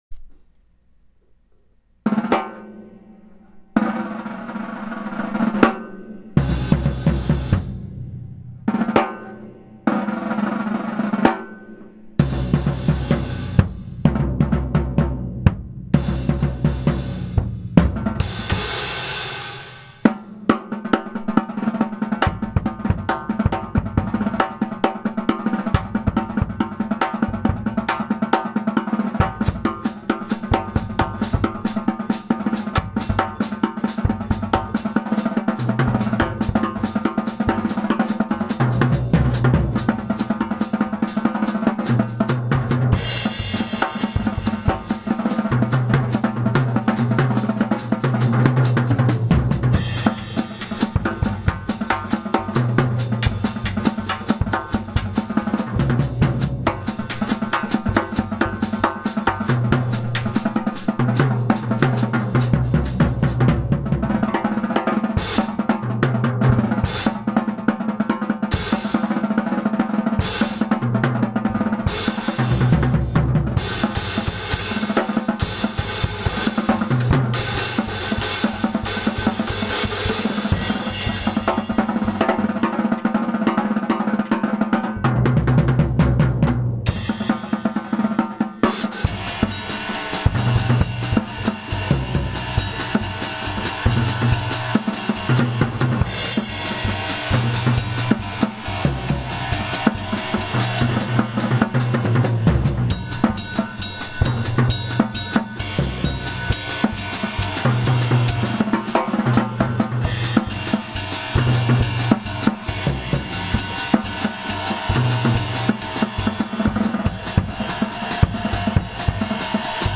02 Latin Jam.wma